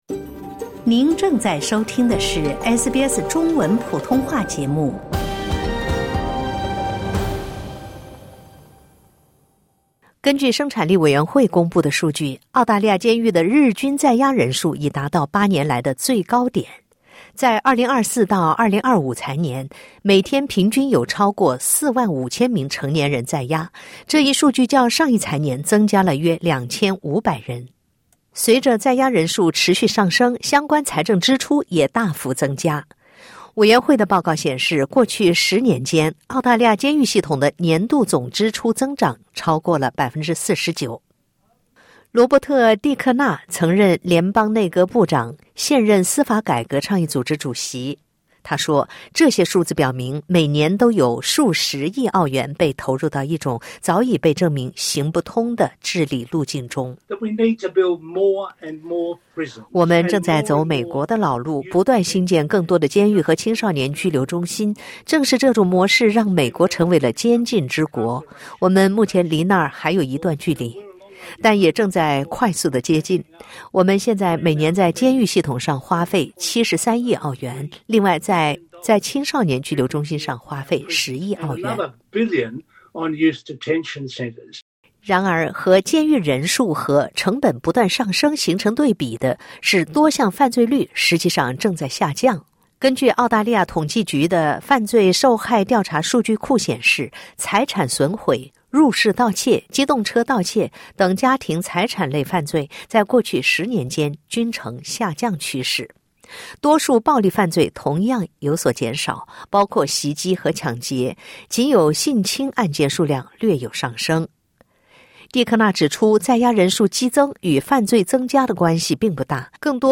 （点击音频收听报道）